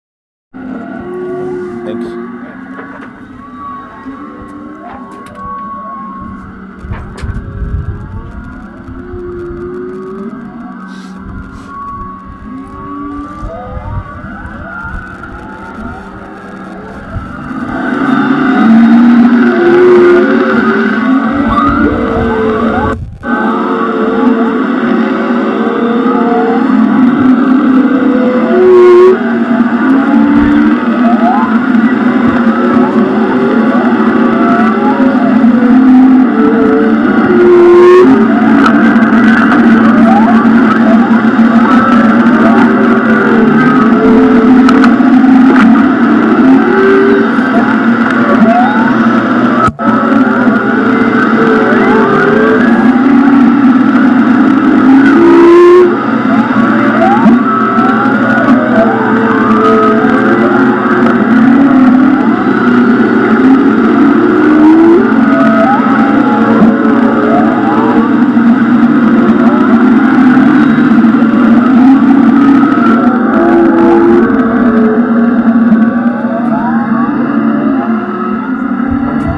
humpback serenade
Humpback whales are known for their complex and melodic vocalizations, often referred to as 'songs'. These sequences of moans, howls, and cries are primarily produced by males during the breeding season.
Hydrophones, or underwater microphones, are used to capture these sounds.